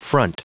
Prononciation du mot front en anglais (fichier audio)